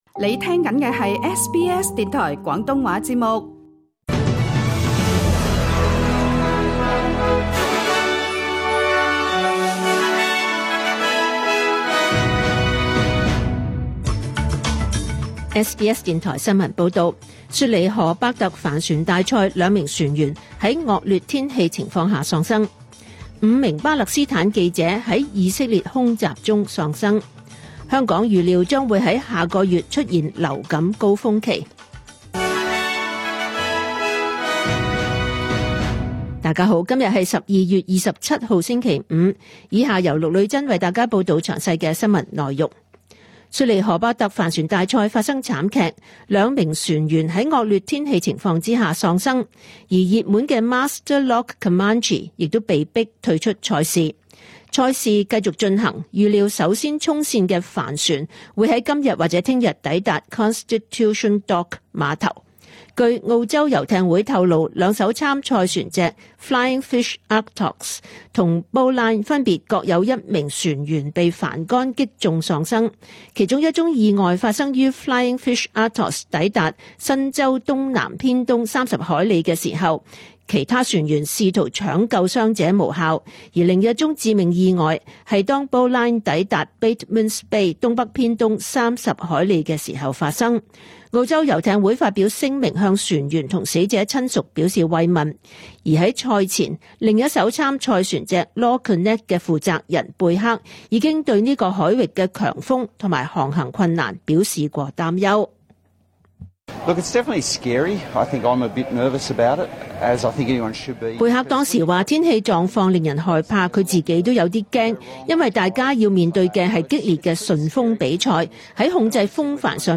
2024 年 12 月 27 日 SBS 廣東話節目詳盡早晨新聞報道。